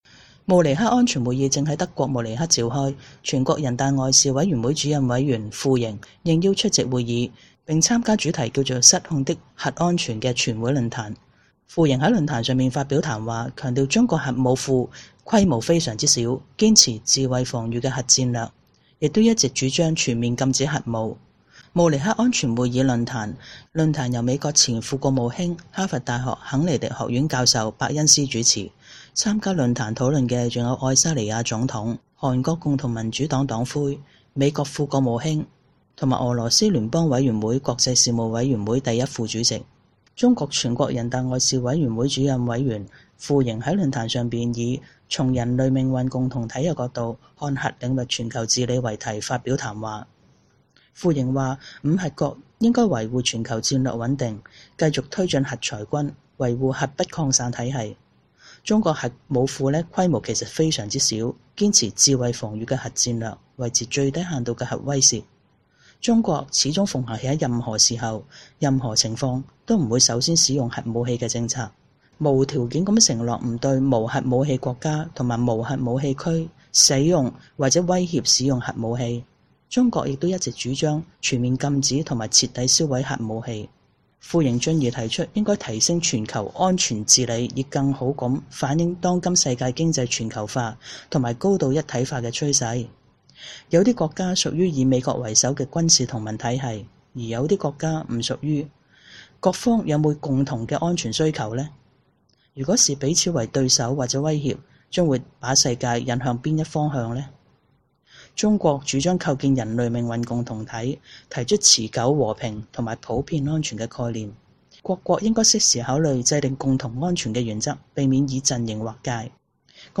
慕尼黑安全會議召開，全國人大外事委員會主任委員傅瑩出席，並参與論壇，並回應「中國威脅論」